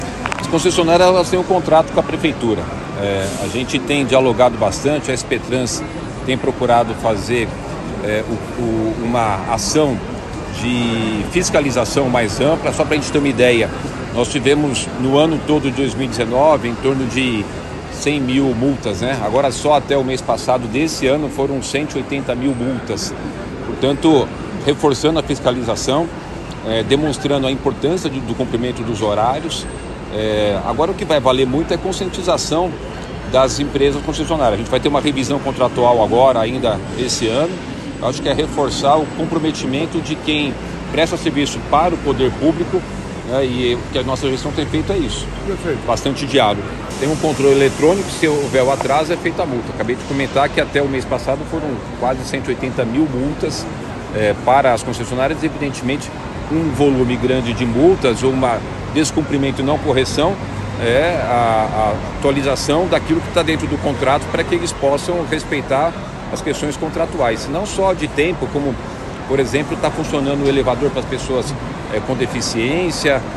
De acordo com o prefeito Ricardo Nunes, em entrevista coletiva nesta segunda-feira, 28 de agosto de 2023, após entrega da revitalização do Terminal Santo Amaro, na zona Sul de São Paulo, as fiscalizações sobre a atuação das companhias de ônibus da cidade serão intensificadas.